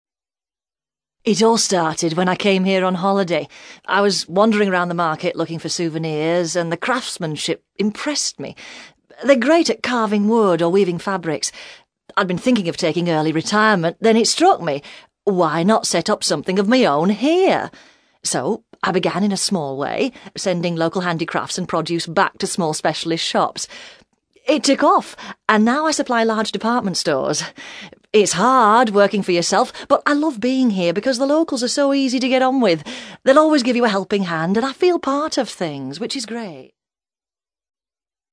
ACTIVITY 112: You will hear five short extracts in which British people are talking about living abroad.